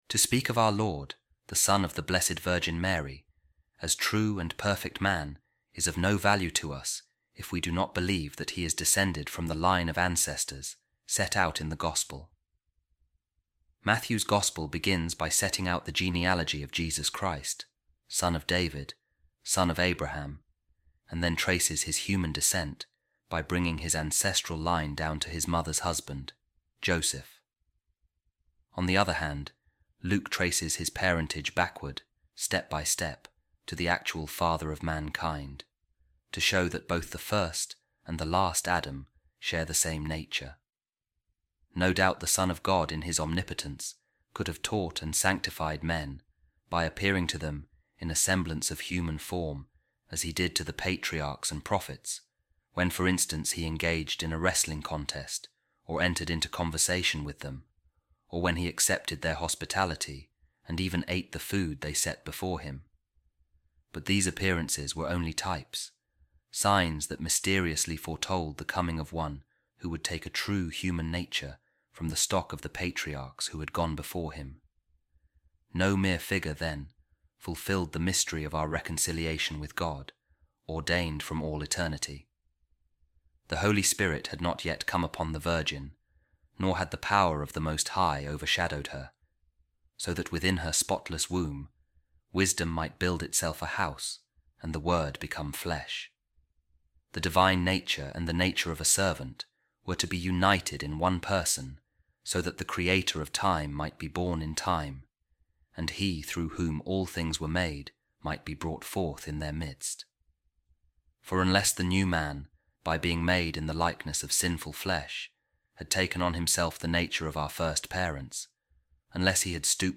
A Reading From A Letter Of Pope Saint Leo The Great | The Mystery Of Our Reconciliation With God